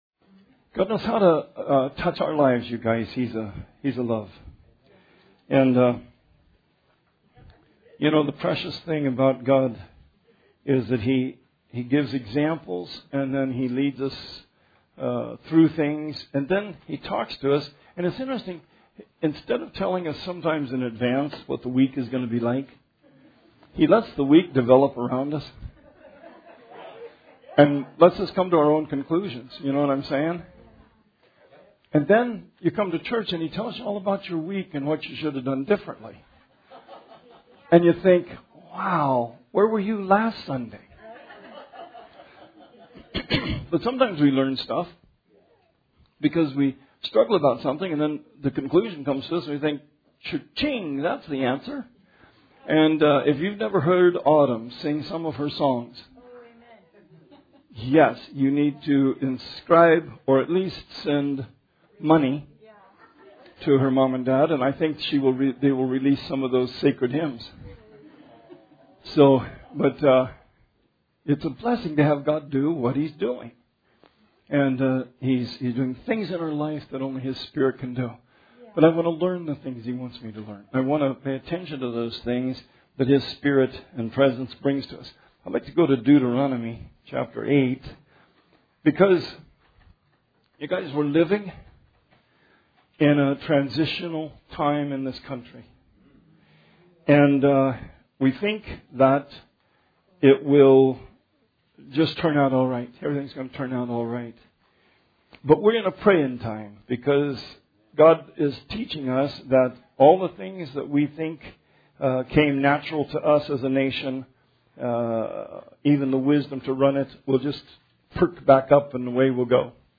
Sermon 6/28/20